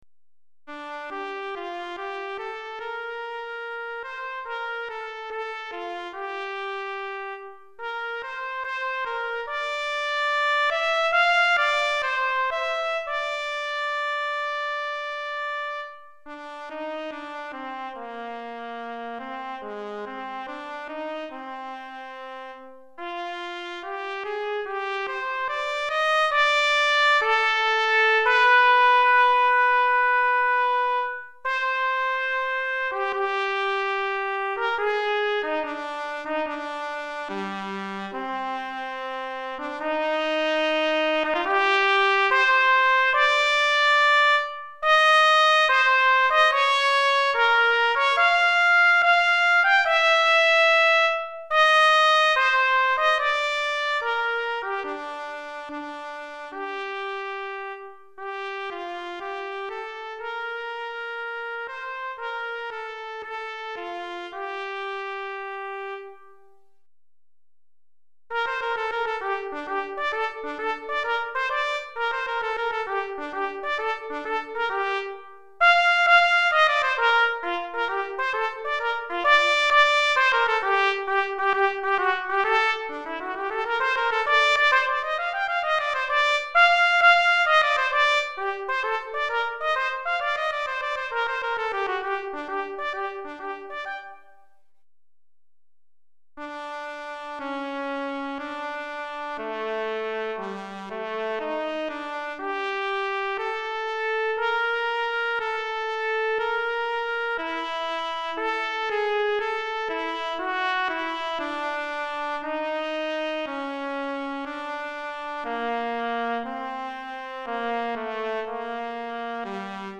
pour trompette ou cornet solo DEGRE CYCLE 2